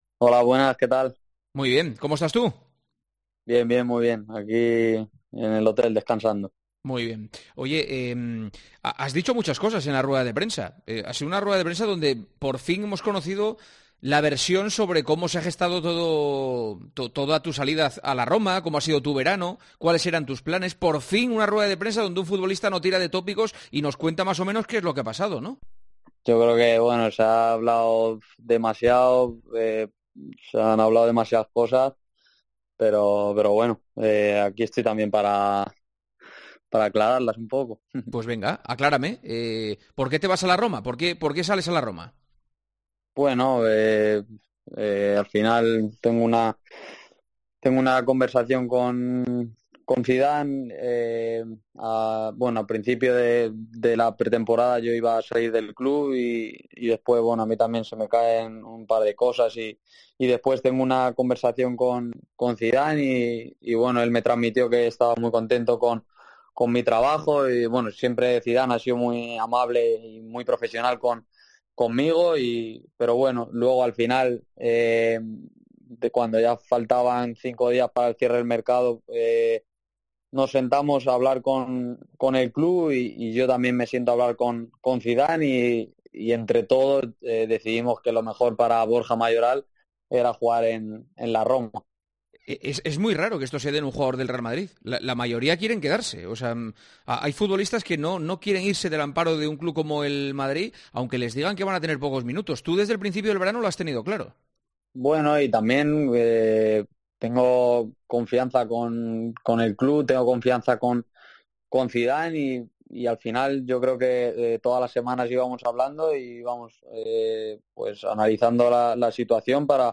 En este día, también ha atendido a los micrófonos de COPE.